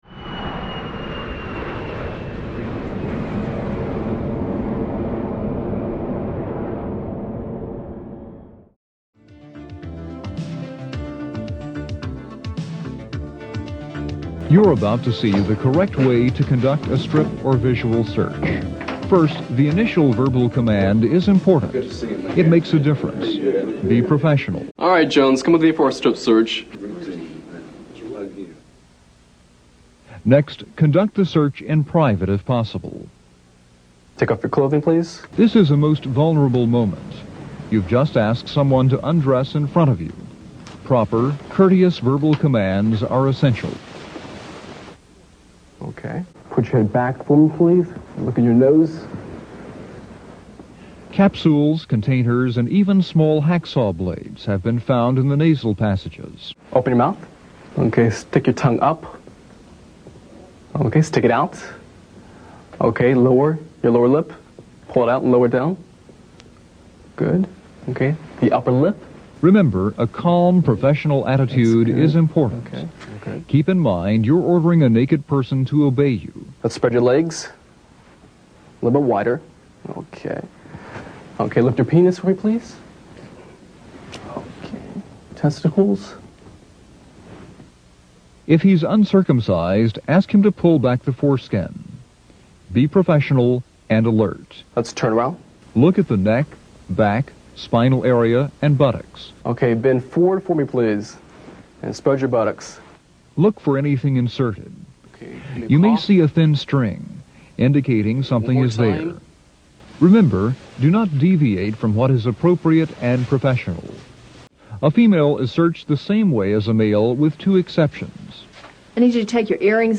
What follows is an excerpt from a training video for prison guards on how to make sure that inmates aren't hiding contraband.